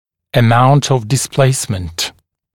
[ə’maunt əv dɪs’pleɪsmənt][э’маунт ов дис’плэйсмэнт]величина смещения